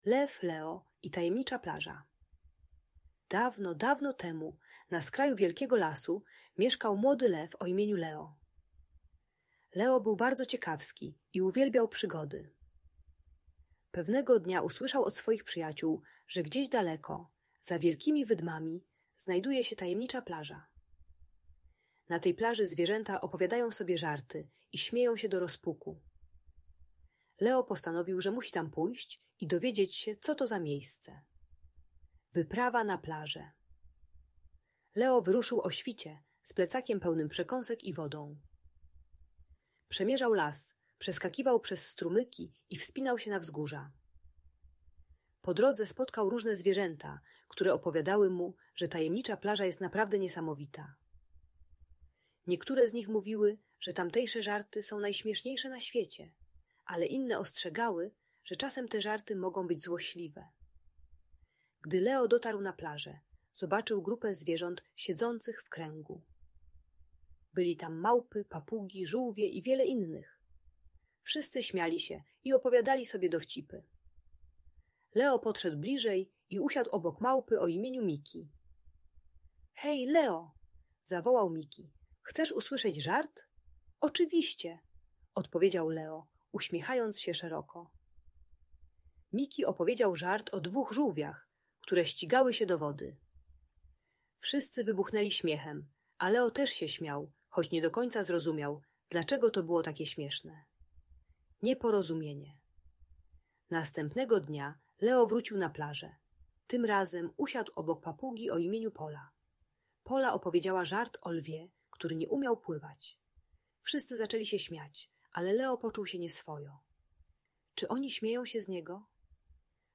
Lew Leo i Tajemnicza Plaża - historia o przyjaźni i zrozumieniu - Audiobajka dla dzieci